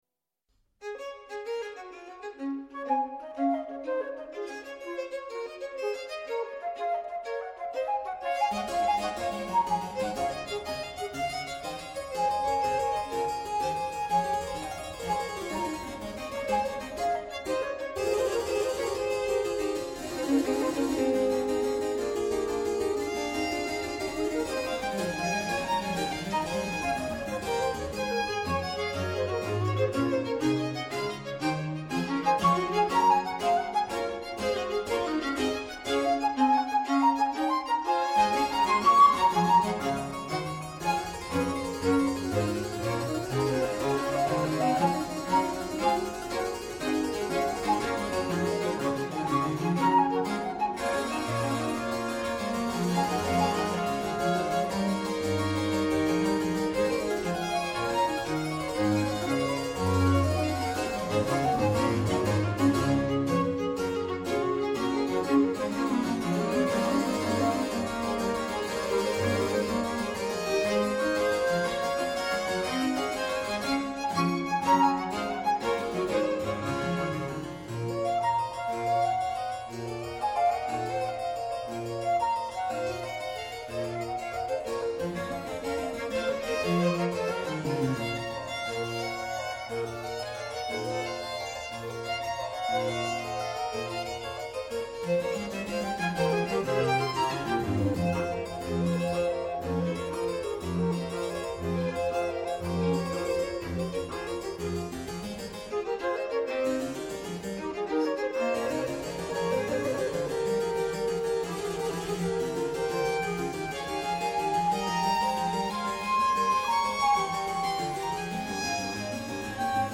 με χιούμορ και θετική διάθεση, μουσική από όλο το φάσμα της ανθρώπινης δημιουργίας δίνοντας έμφαση στους δημιουργούς